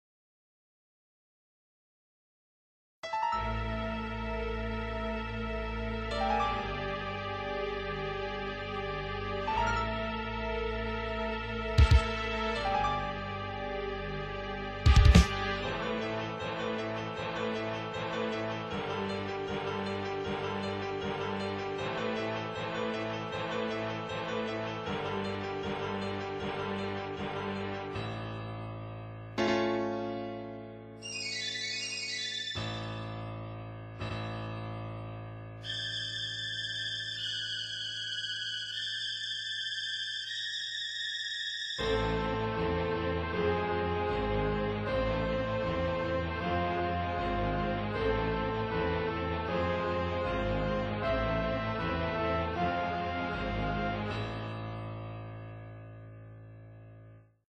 در پایان می توانید نمونه صوتی قطعه ها که با سمپل تهیه شده اند به همراه تعدادی از پی دی اف های آماده شده را در زیر این مطلب دانلود نمایید.
۷- فریژین- کاربردی در ایجاد فضای ترسناک در موسیقی تلویزیون
7-Phrygian-tv-music.wma